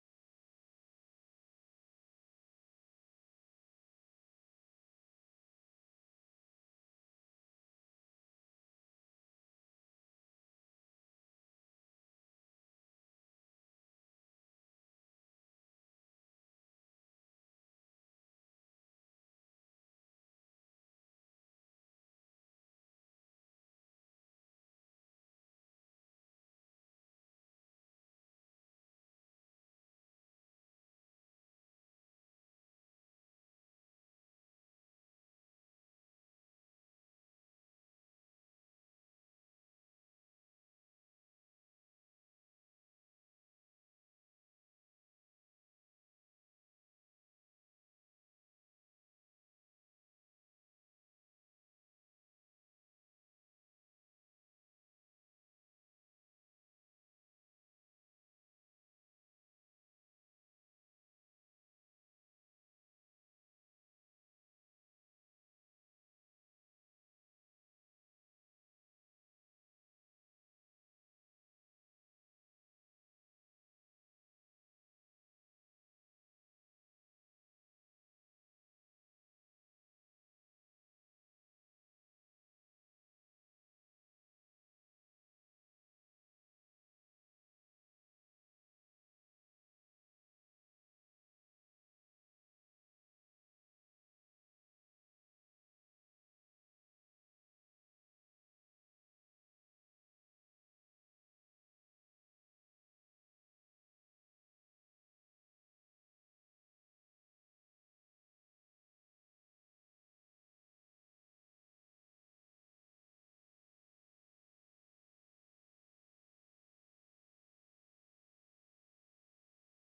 Praise and Worship on January 26 2025 at FWC